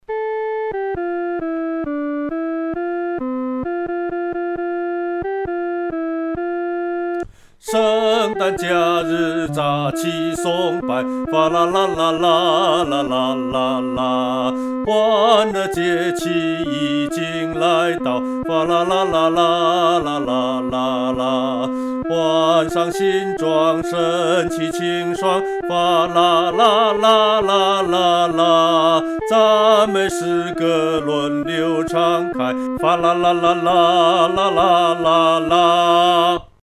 独唱（第二声）